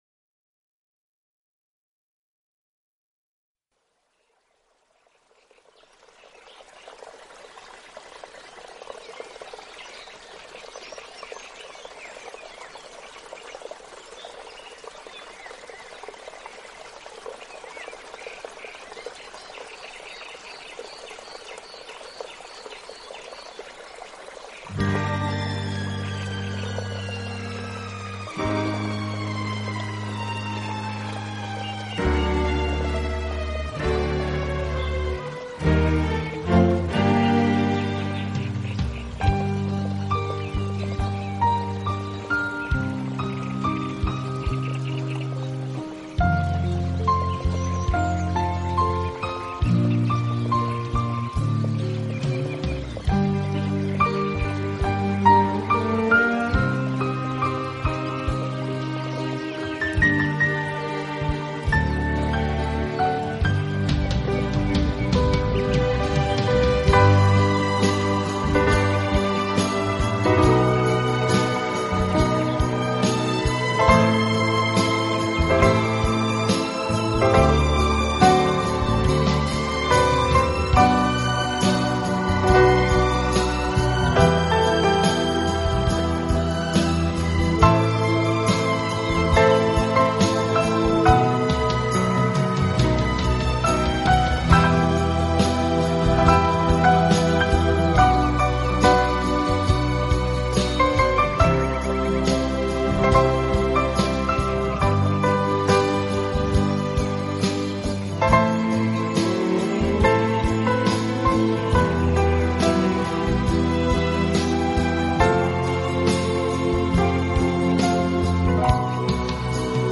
自然聲響與音樂的完美對話
海浪、流水、鳥鳴，風吹過樹葉，雨打在屋頂，
大自然的原始採樣加上改編的著名樂曲合成了天籟之音。